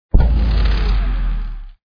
shield_offline.wav